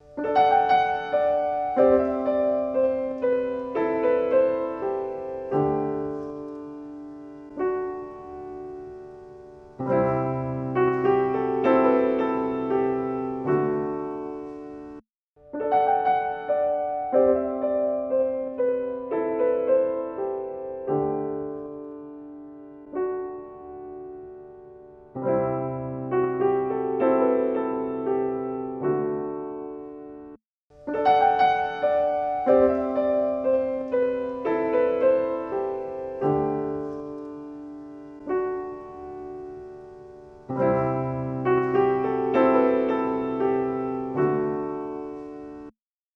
EQ45 | Piano | Preset: Bright Piano
EQ45-Bright-Piano.mp3